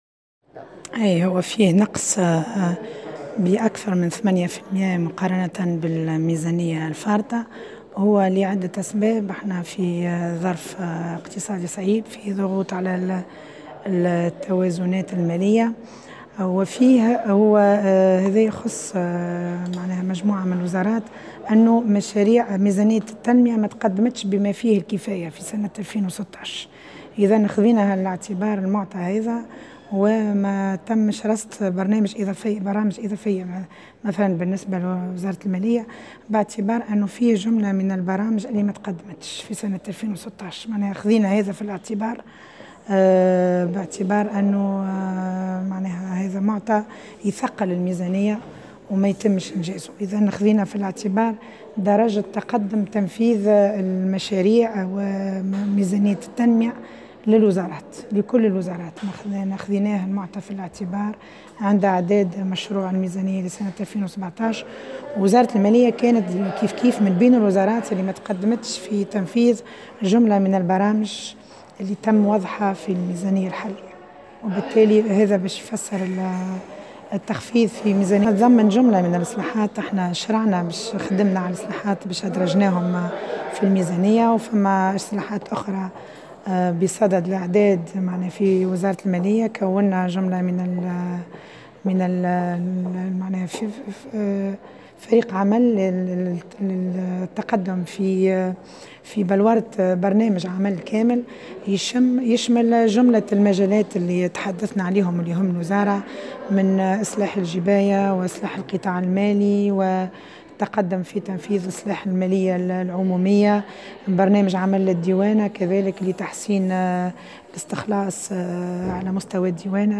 وجاء ذلك في تعقيبها على إستفسارات النواب في جلسة استماع بلجنة المالية بمجلس نواب الشعب، مساء اليوم الاثنين، مشيرة إلى أنه سيتم تخصيص 100 مليون دينار لدعم المؤسسات الصغرى والمتوسطة التي تشكو من صعوبات مالية، بحسب ما نقلته وكالة الأنباء الرسمية "وات".وأوضحت، من جهة أخرى، أن الوزارة بصدد وضع برنامج عمل لتعصير إدارة الجباية، ومشددة على أن تحسين الإستخلاص يمر عبر تعصير الإدارة وتوفير الموارد البشرية وتطوير برامج العمل ورقمنة المعاملات الإدارية.